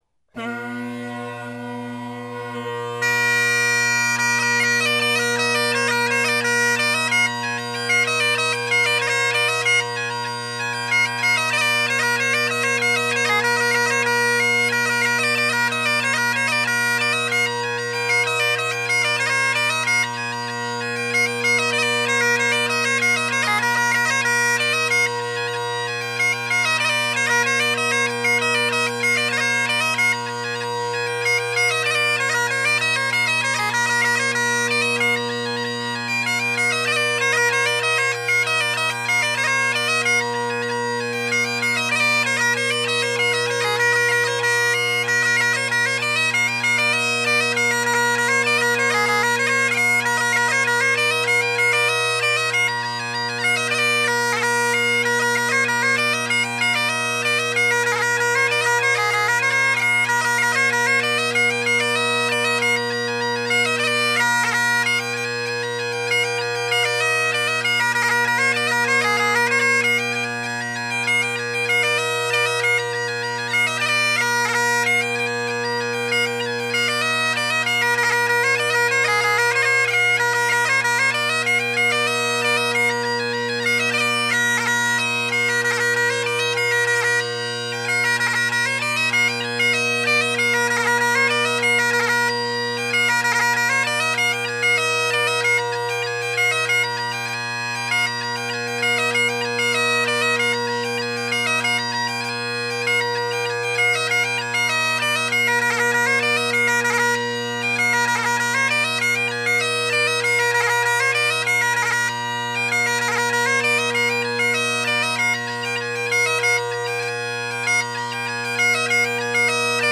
Drone Sounds of the GHB, Great Highland Bagpipe Solo, Reviews
You’ll notice at the end the pitch is a bit variable before I cut out, something that isn’t present in the second recording with a different chanter and reed.
So it’s the AyrFire chanter again in the MacPherson’s only this time I’ve gone back to the HHD tenor and Crozier glass bass combo.
macphersons_hhd-tenors_crozier-glass-bass_ayrfire_maclellan.mp3